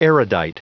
Prononciation du mot erudite en anglais (fichier audio)
Prononciation du mot : erudite